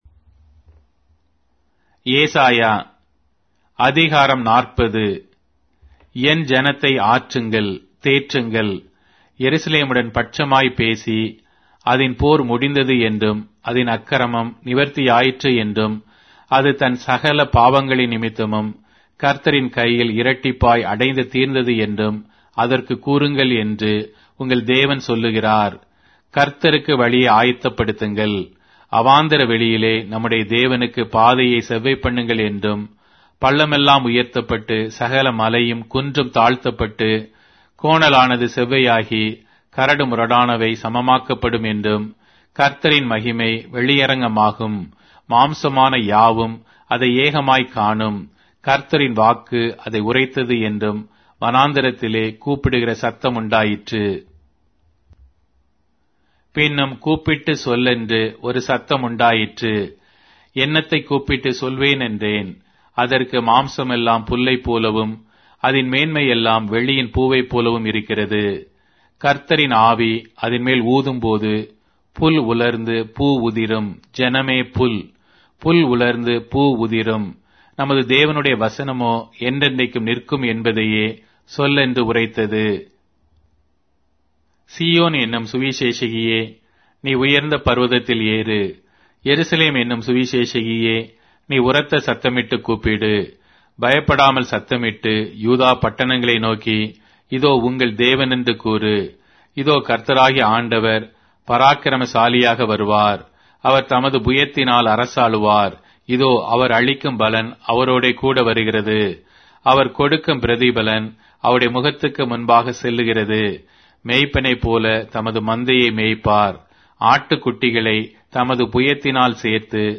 Tamil Audio Bible - Isaiah 62 in Tev bible version